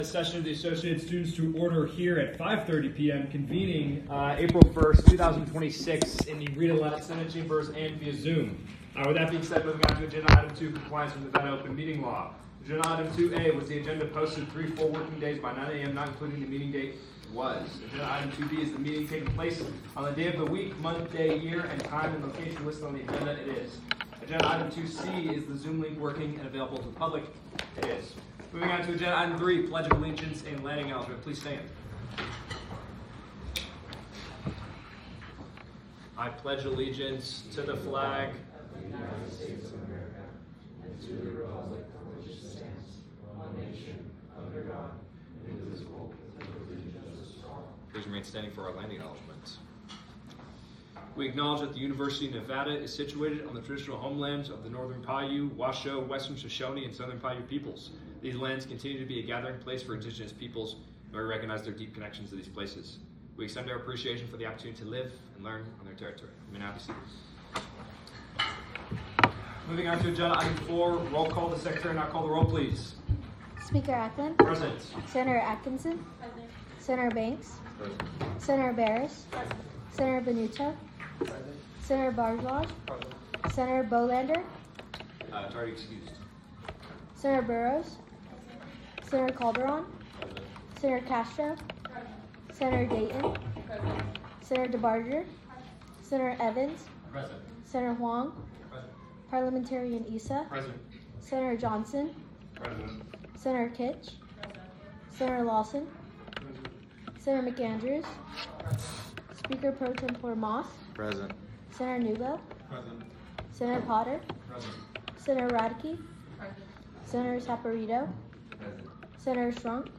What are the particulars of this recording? Location : Rita Laden Senate Chambers